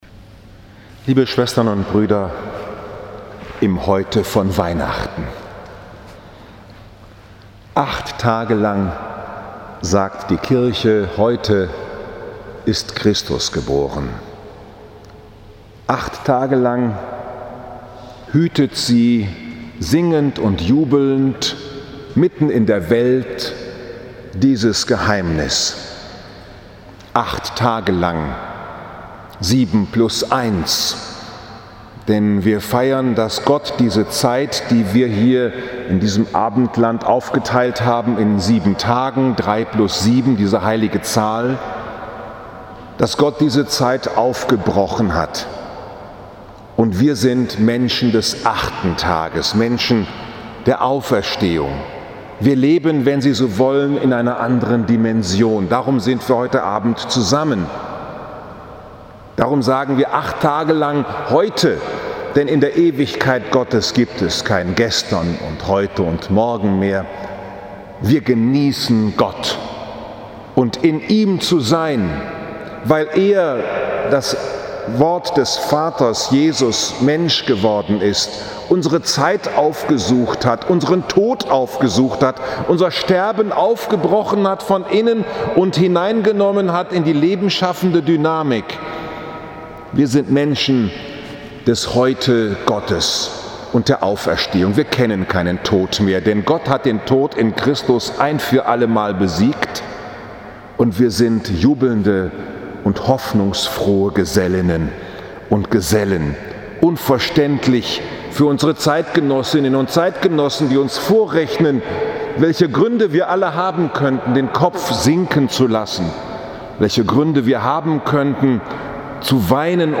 Familie ist Anfangen, Auffangen und in Freiheit entlassen Von der göttlichen Dynamik als Grundlage des christlichen Familienbegriffes 29. Dezember 2018, 18 Uhr, Frankfurter Dom, Fest der hl. Familie